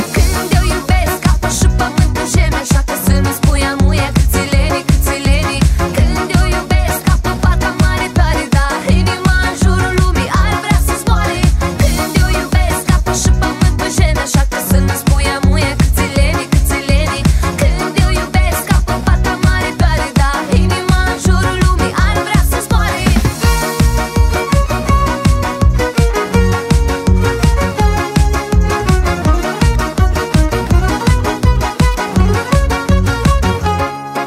• Качество: 320, Stereo
поп
Молдавская танцевальная